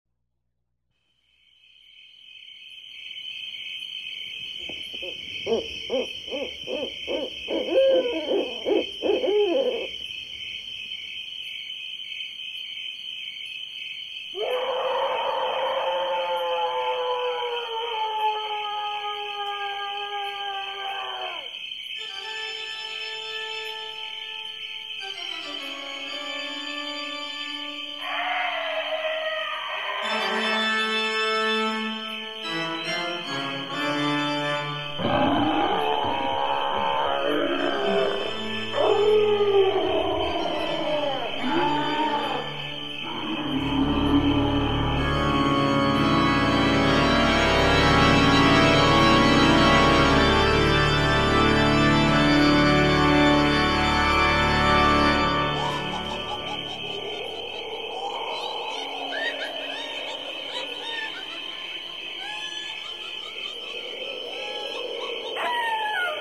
witch sound